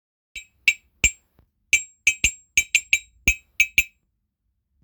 ●ベル
現地では、人差し指に本体をリングを親指に装着し、演奏します。手の中に納まる小さいサイズですが、腰のあるサウンドなので、いろいろ応用して使える鉄楽器です。
素材： 鉄